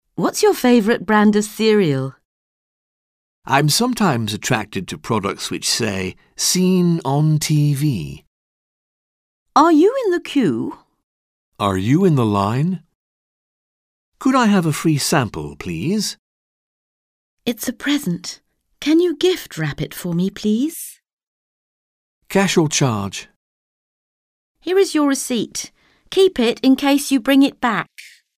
Un peu de conversation - Faire les courses, acheter des vêtements